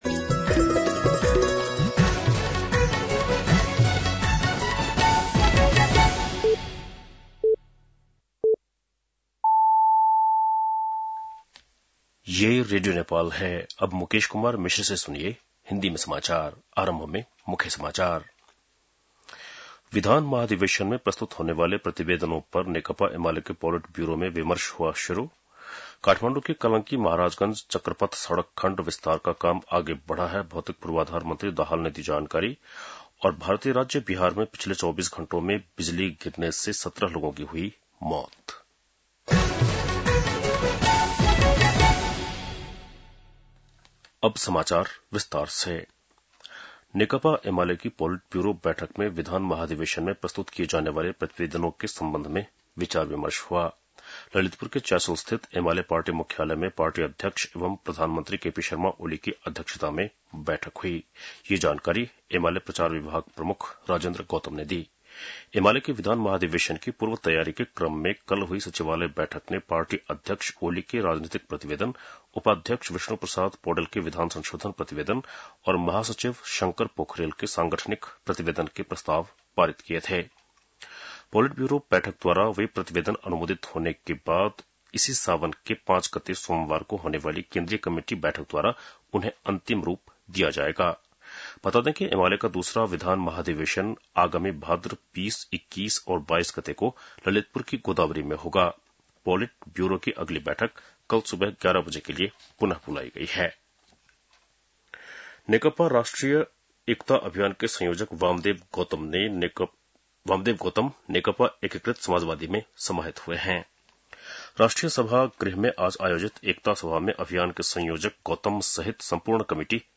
बेलुकी १० बजेको हिन्दी समाचार : २ साउन , २०८२